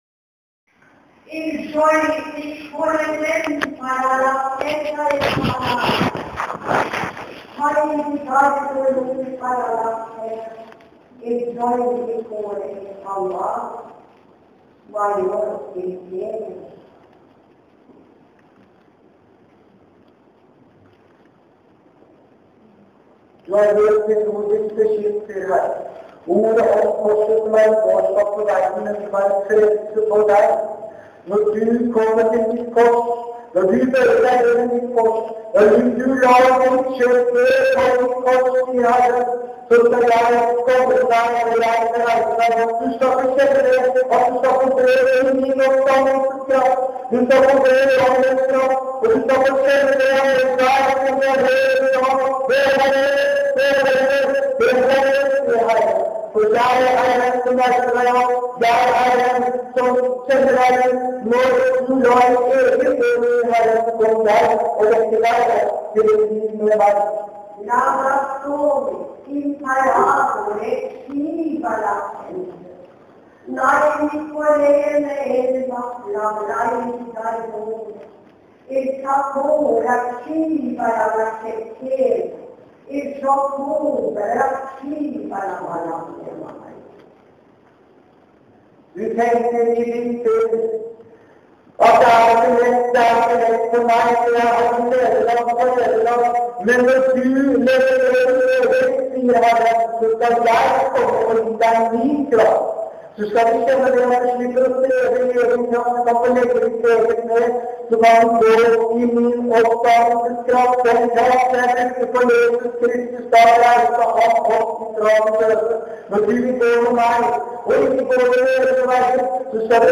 Tyding av tungetale.